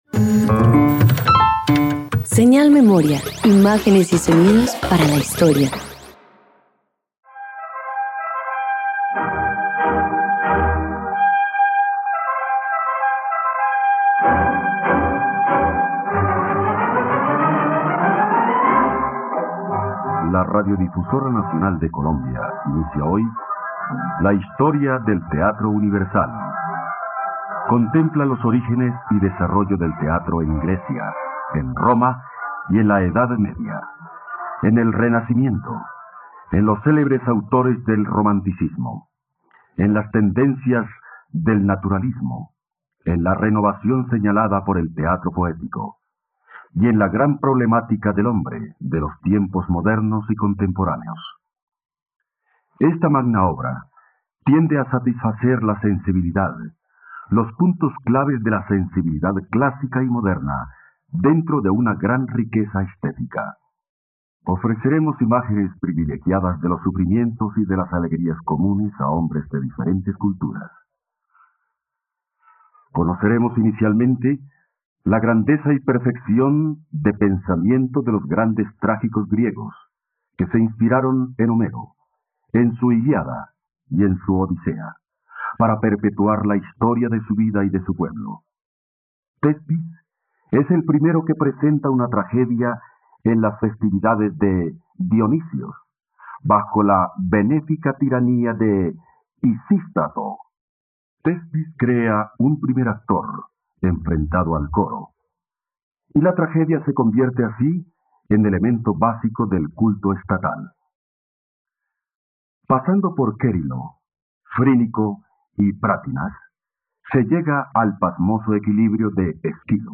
..Radioteatro. Escucha la adaptación del mito griego de Prometeo del dramaturgo griego Esquilo en la plataforma de streaming RTVCPlay.